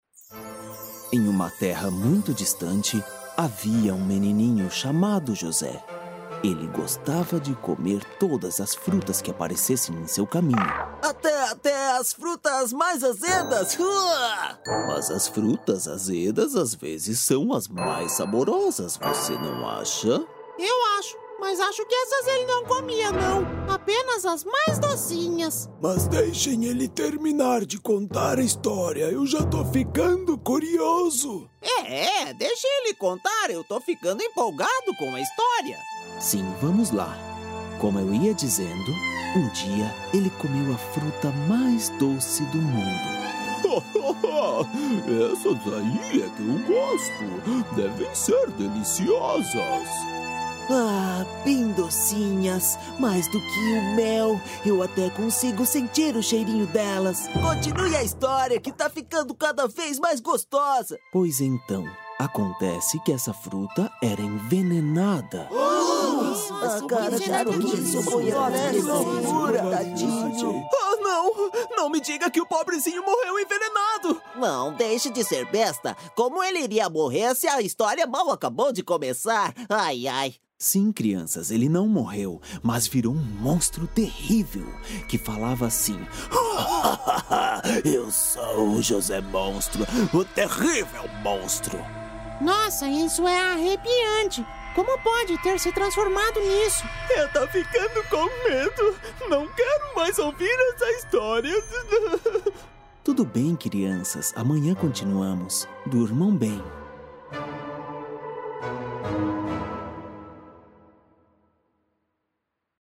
Animación
Neumann TLM193 Microphone
Acoustic and soundproof Booth
BarítonoBajo